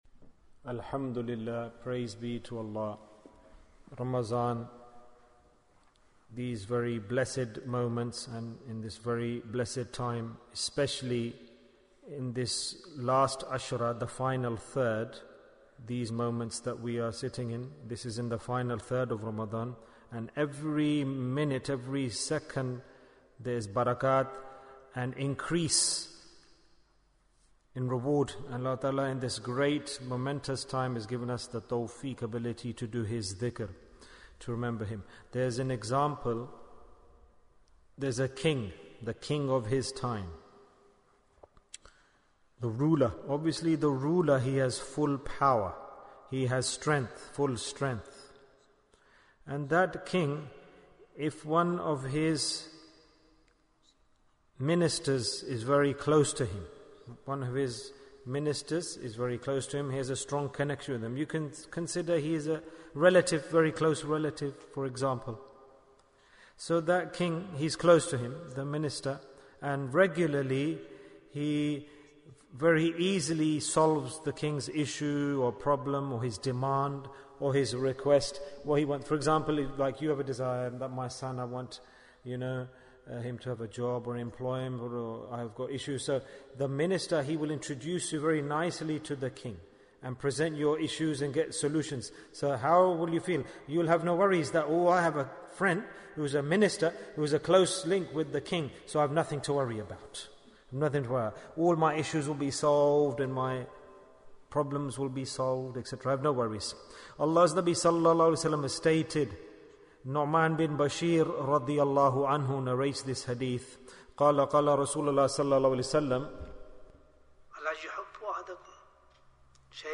Bayans Clips Naat Sheets Store Live How Does Dhikr Help? Bayan, 30 minutes 3rd May, 2021 Click for Urdu Download Audio Comments How Does Dhikr Help?